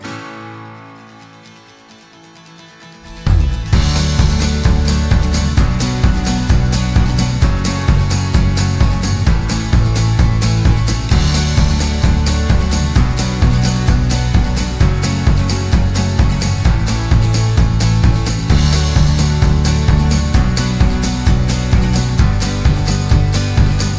no Backing Vocals Pop (2010s) 3:37 Buy £1.50